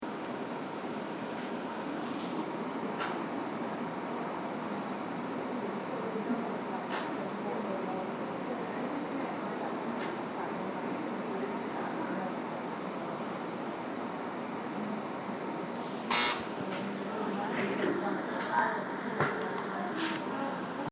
Field Recording 5
Sounds Heard: AC unit squeaking and blowing air, people talking, door buzzer, door opening and closing.
Tada-Theatre.mp3